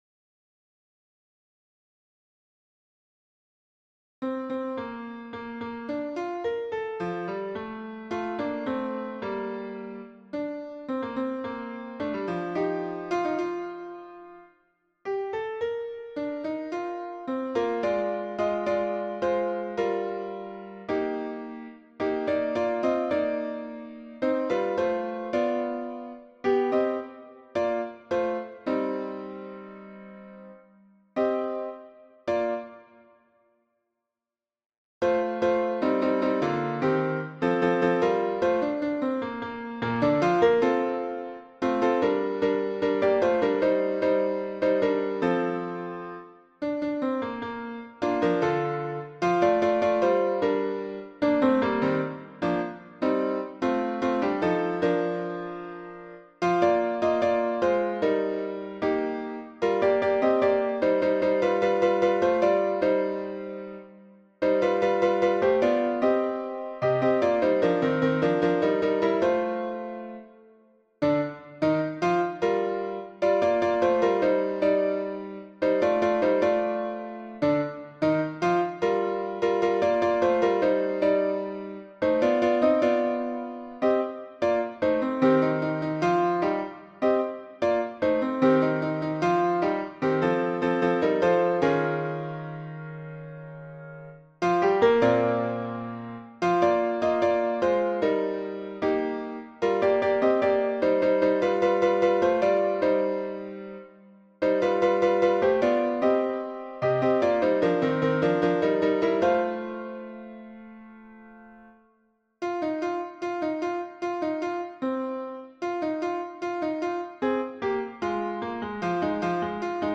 MP3 version piano (toutes les voix)